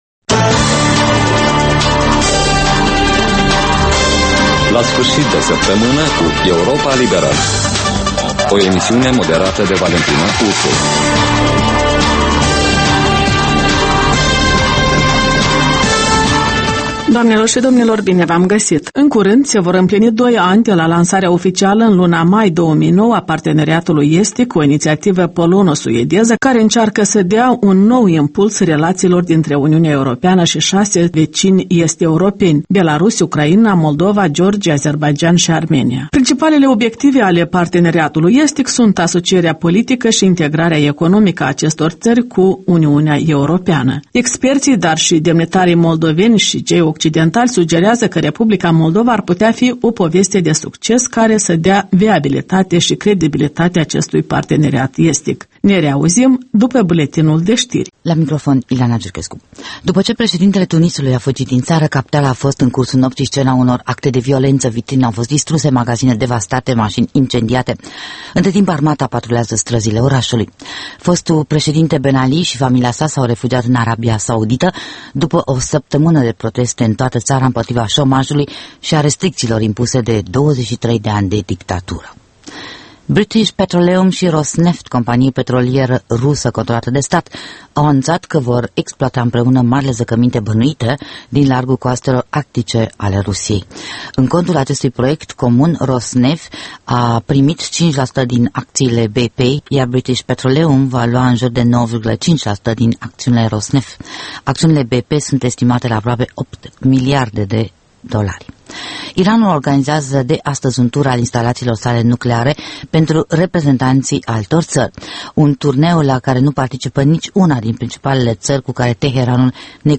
In fiecare sîmbătă, un invitat al Europei libere semneaza "Jurnalul săptămînal".